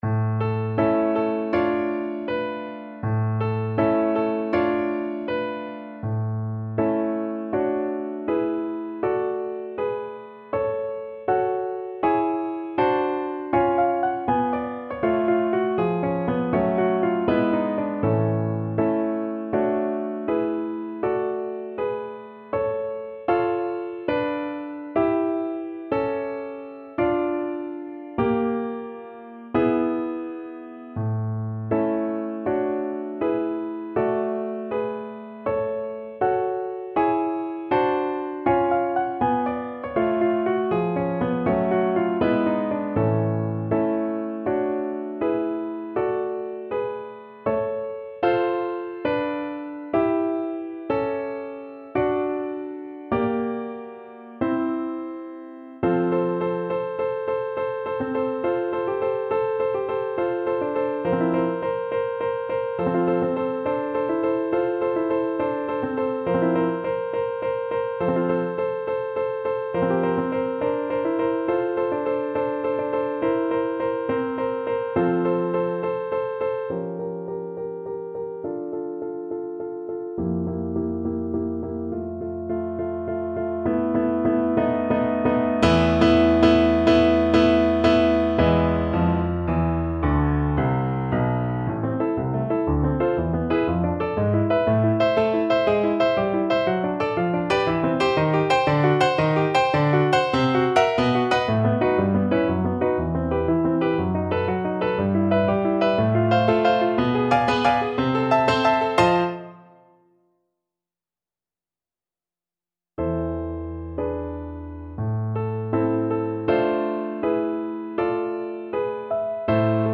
2/4 (View more 2/4 Music)
Moderato =80
Classical (View more Classical Viola Music)